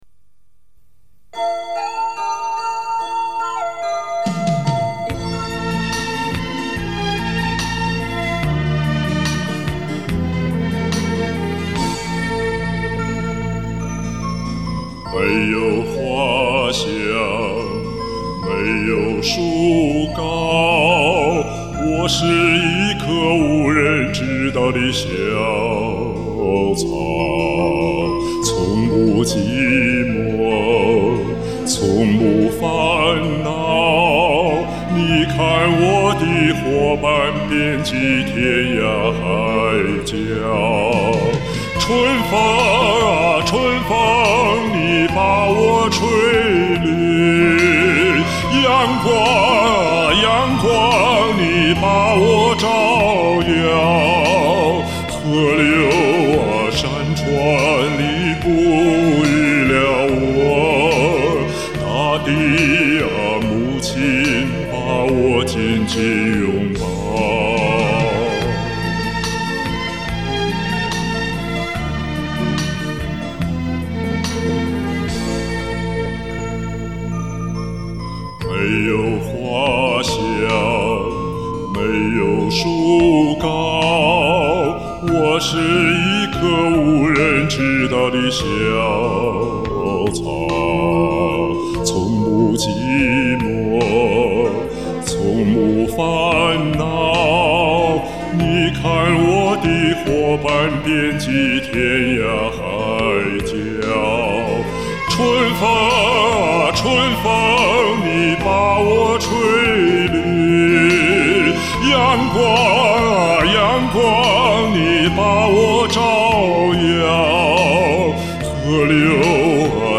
打动人的沉稳深情。
主歌沉稳深情副歌唱高昂激情，就喜欢主副歌这样大起大落的处理！